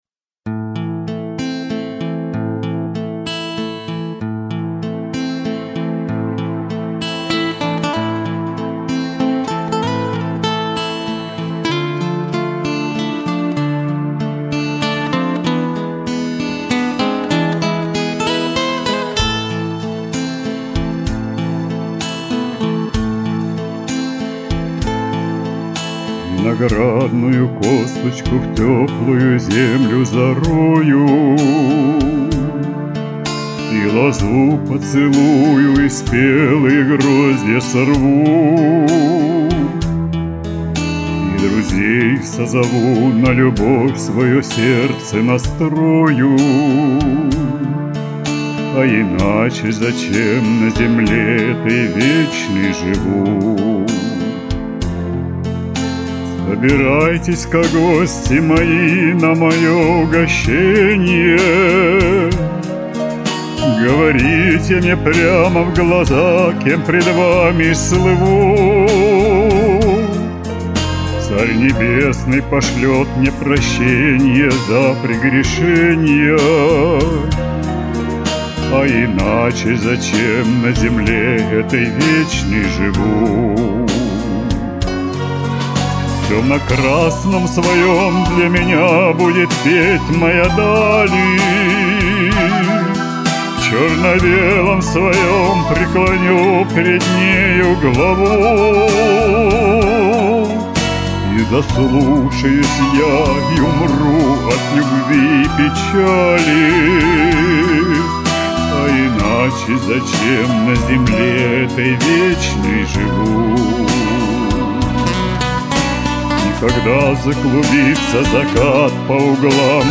Исключительный по звучанию минус.